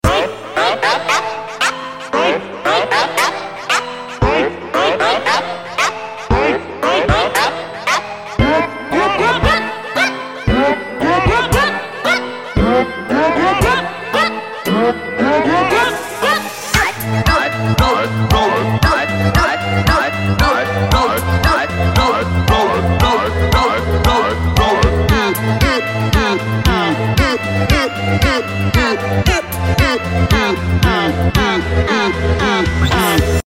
Remix (Battle Cover)